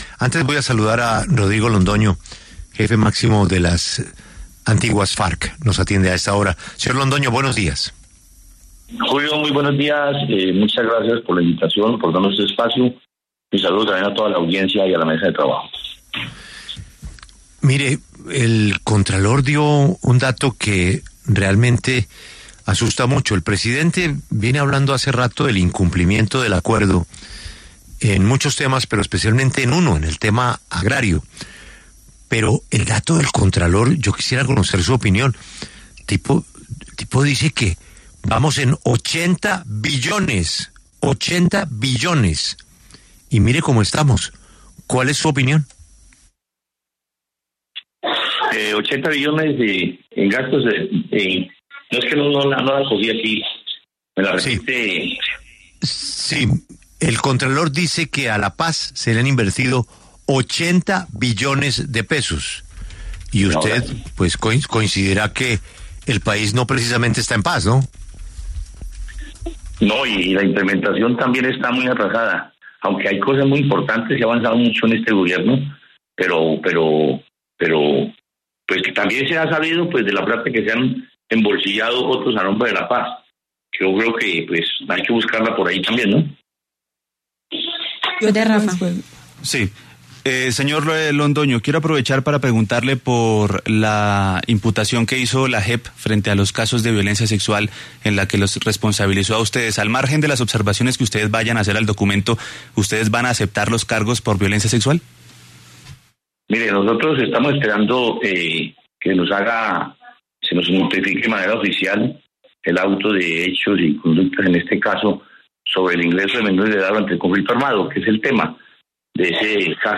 En entrevista con La W, el presidente del Partido Comunes y excomandante de las Farc, Rodrigo Londoño, se refirió a la conmemoración de los ocho años del Acuerdo de Paz y señaló que la implementación de lo pactado va muy lento frente a lo que esperaban.